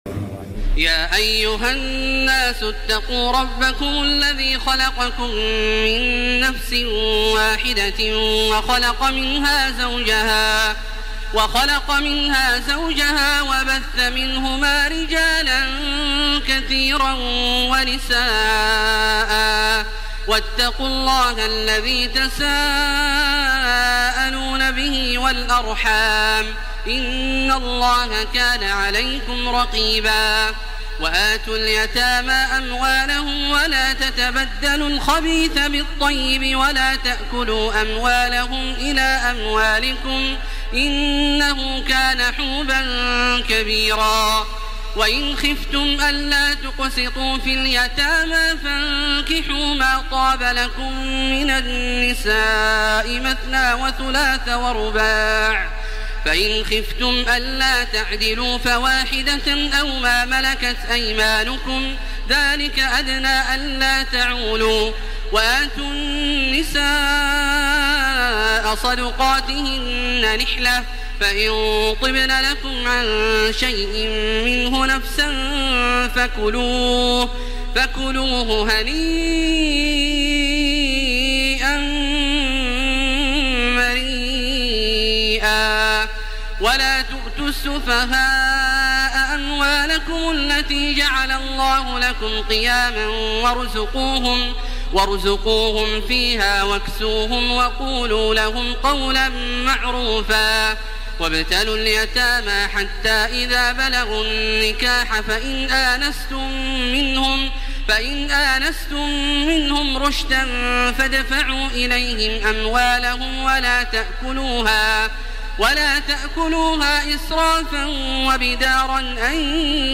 سورة النساء Surat AnNisa > مصحف الشيخ عبدالله الجهني من الحرم المكي > المصحف - تلاوات الحرمين